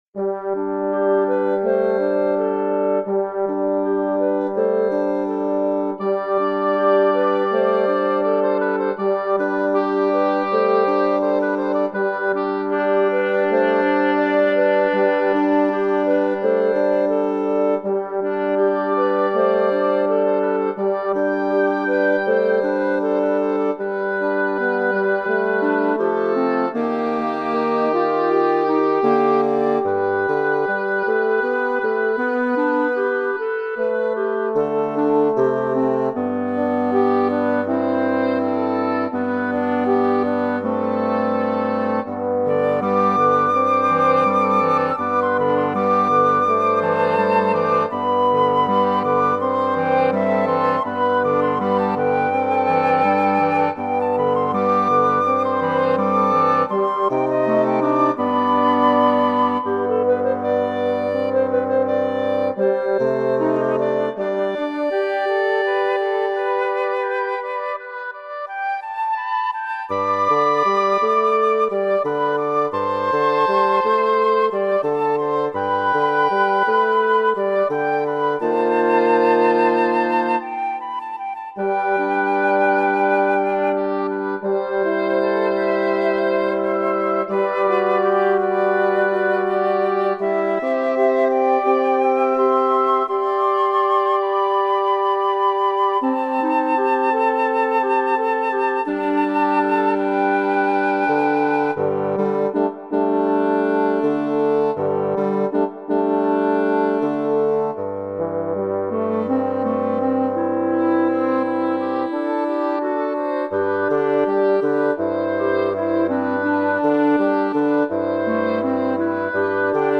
Voicing: Woodwind Quintet